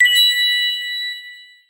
その中で、我々はシンプルで飽きの来ない通知音を求めています。